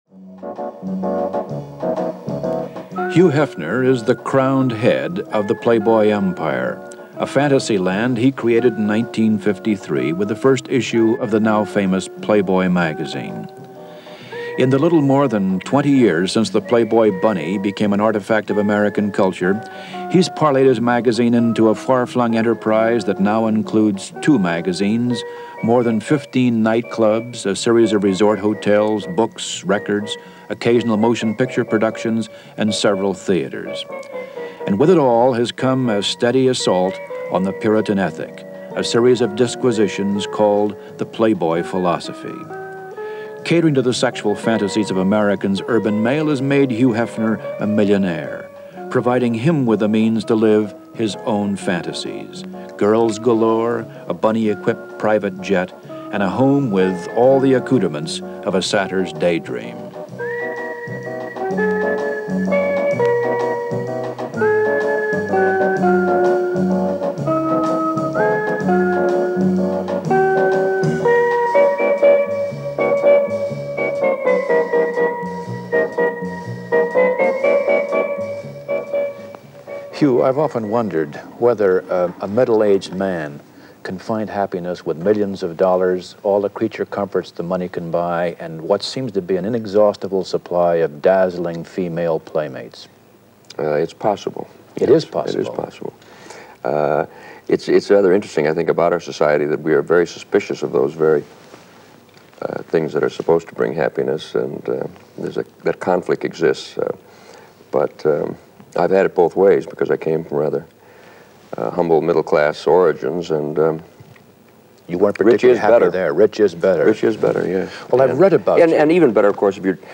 A Word Or Two From Hugh Hefner - 1974 - An interview with legendary publisher of Playboy Magazine Hugh Hefner by James Day - Past Daily Pop Chronicles.